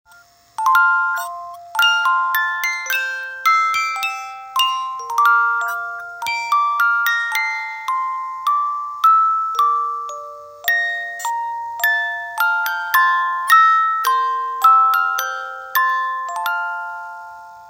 Cover , Divertido